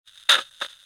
radio_on.ogg